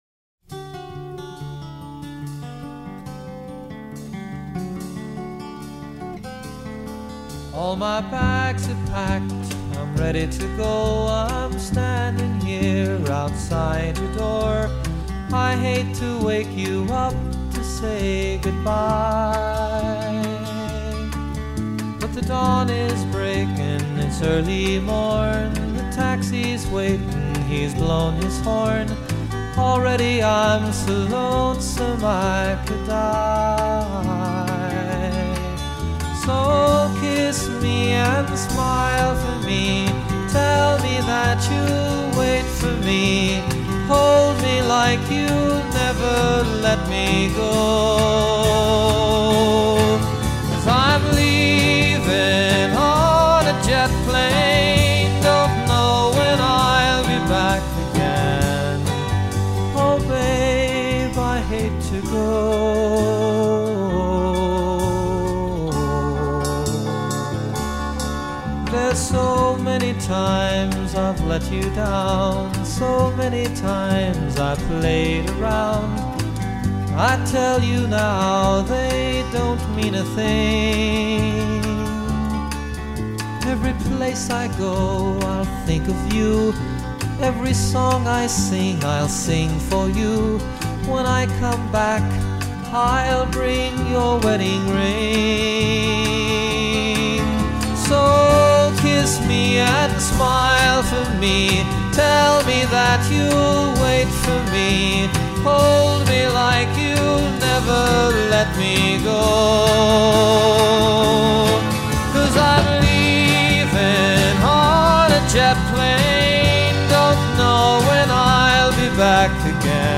چقدر صدای آروم و خاصی داره